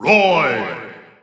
The announcer saying Roy Koopa's name in English and Japanese releases of Super Smash Bros. 4 and Super Smash Bros. Ultimate.
Roy_Koopa_English_Announcer_SSB4-SSBU.wav